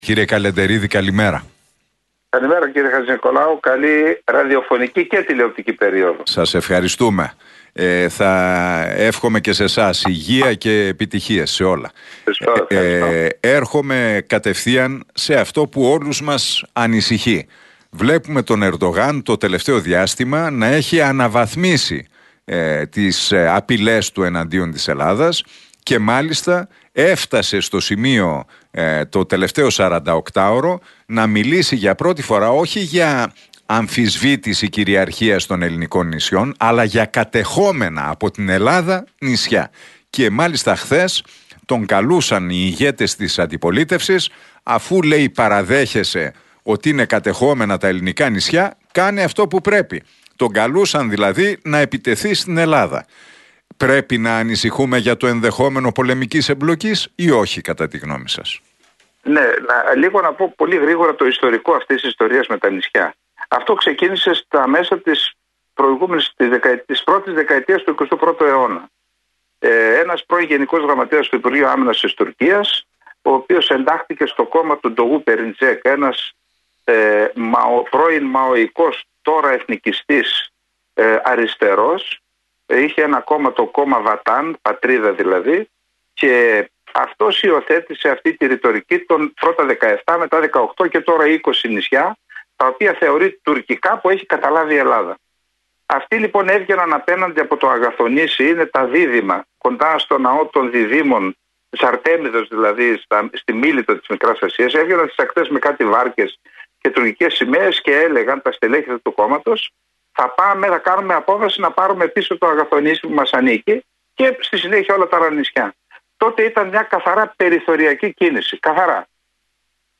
Για τις τουρκικές προκλήσεις μίλησε στον realfm 97,8 και την εκπομπή του Νίκου Χατζηνικολάου